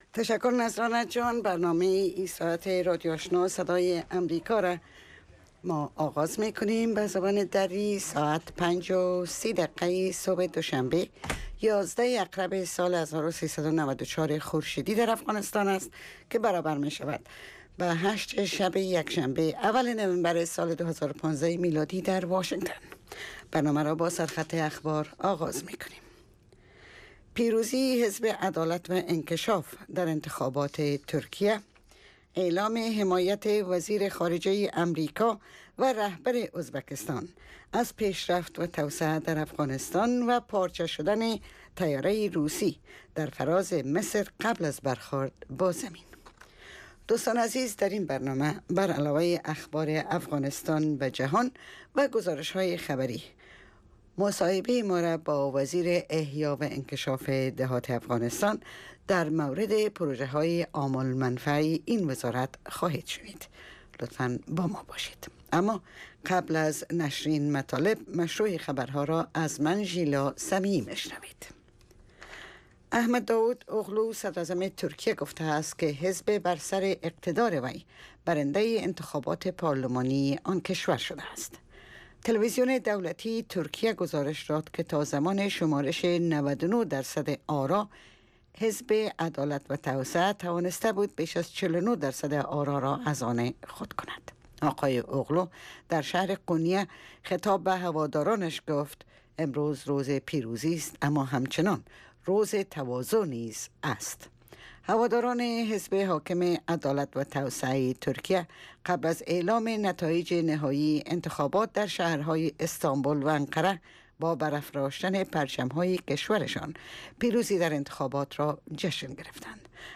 اولین برنامه خبری صبح